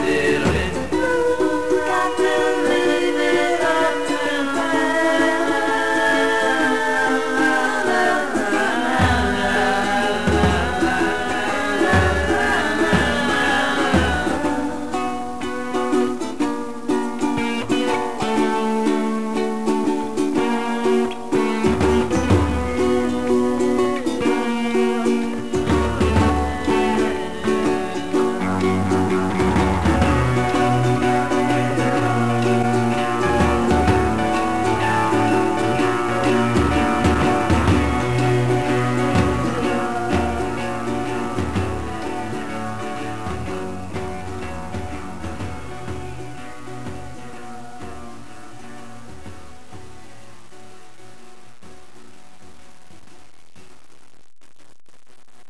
These are lo-fi .wav files, not huuge MP3s.